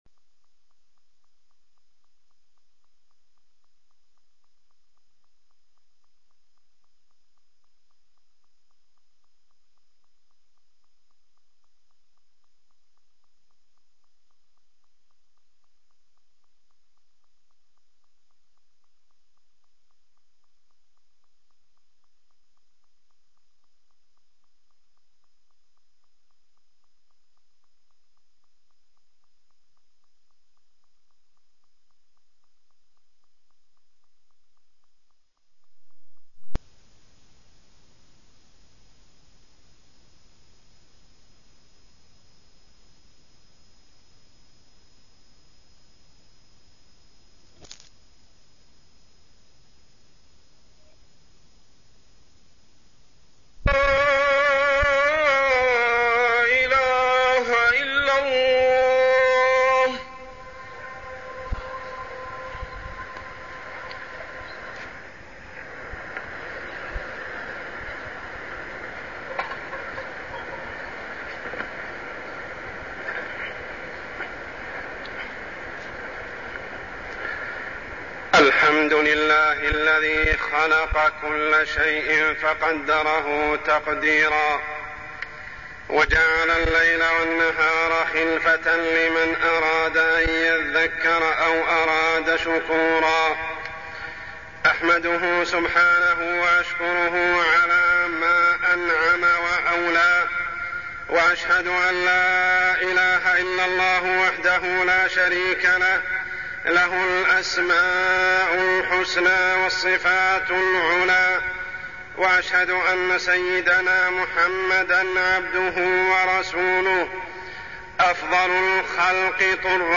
تاريخ النشر ٣٠ ذو الحجة ١٤١٩ هـ المكان: المسجد الحرام الشيخ: عمر السبيل عمر السبيل تذكر هاذم اللذات